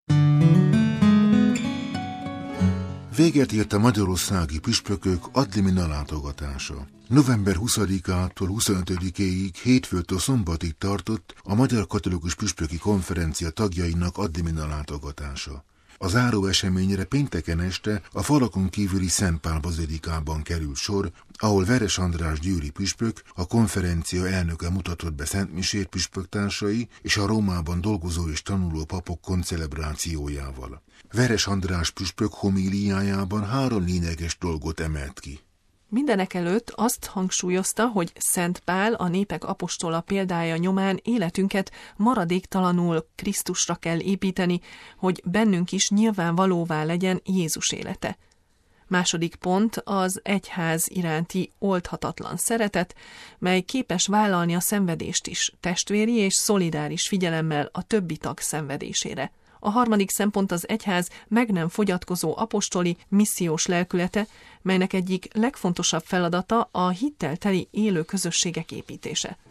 Veres András győri püspök, az MKPK elnökének homíliája